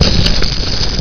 dsflame.wav